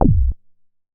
MoogAlicious B.WAV